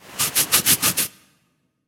scratch.ogg